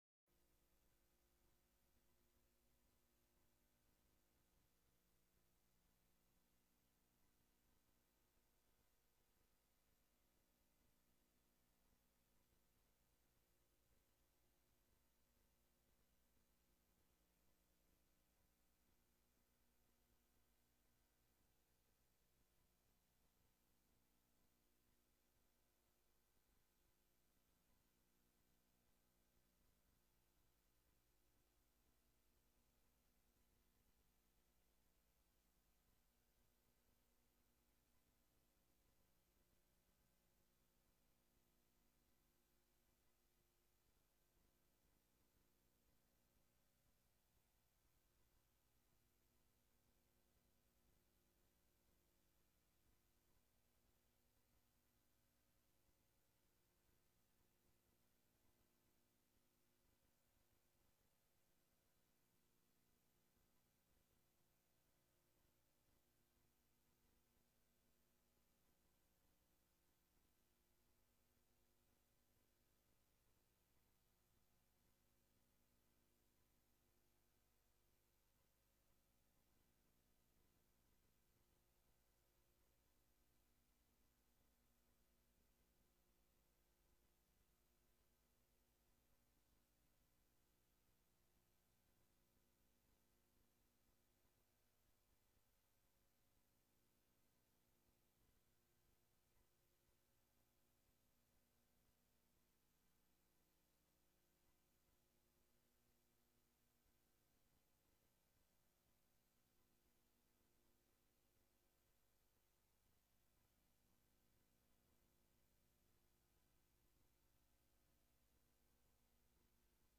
Download de volledige audio van deze vergadering
Locatie: DRU Industriepark - Conferentiezaal